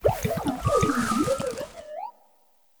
Archivo:Grito de Arboliva.ogg
Categoría:Gritos de Pokémon de la novena generación Categoría:Arboliva